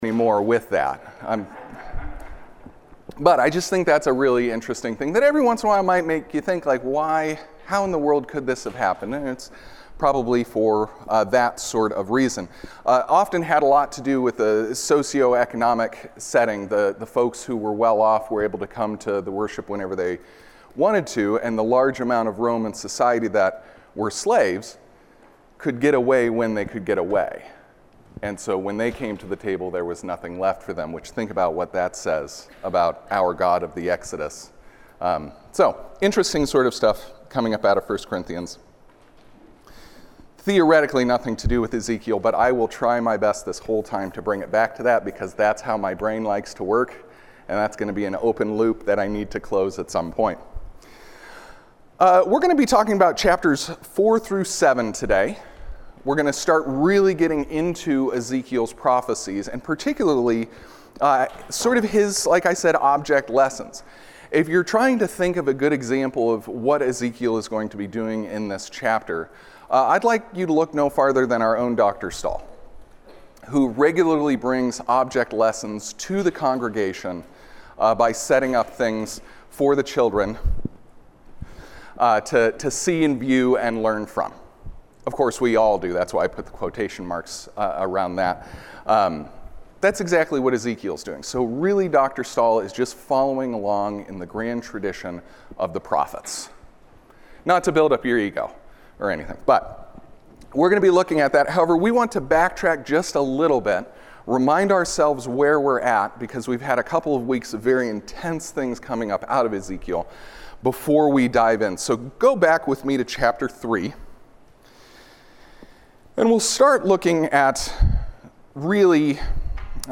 Ezekiel Class 5